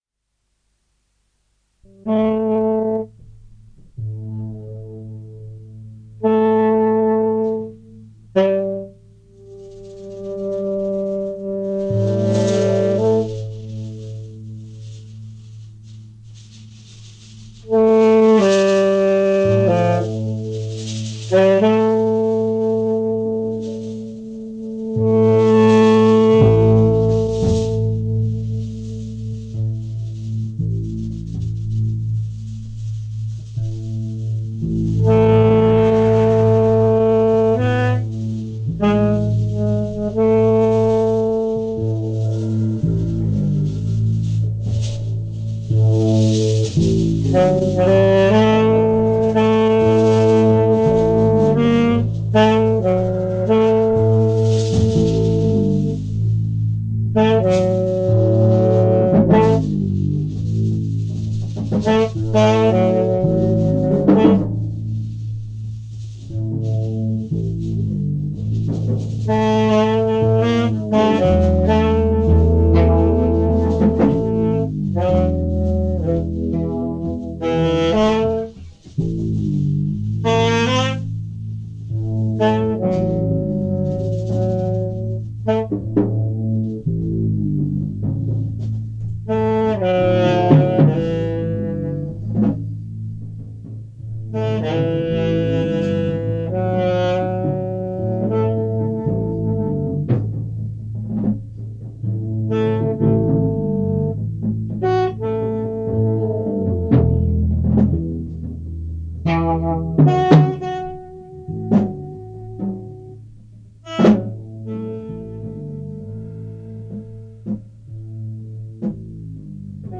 is deze cassette gevonden.
De opname is gemaakt op zolder van het huis en expositieruimte